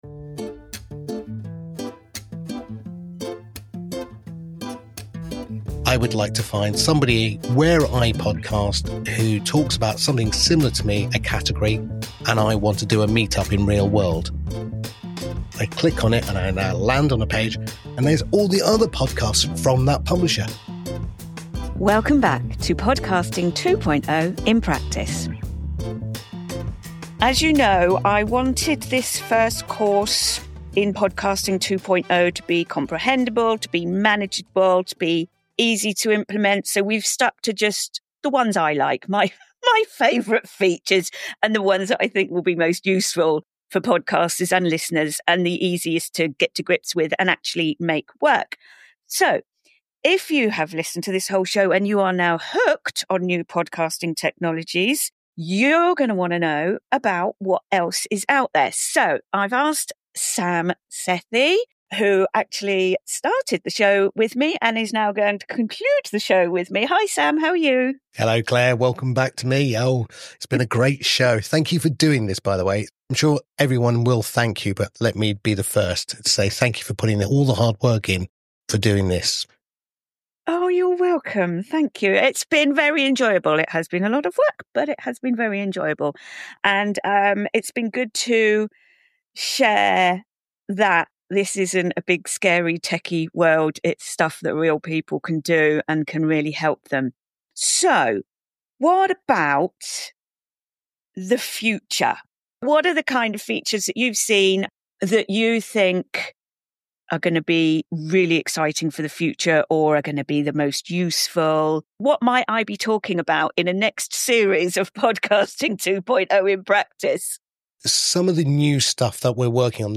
Theme music City Vibe from Ketsa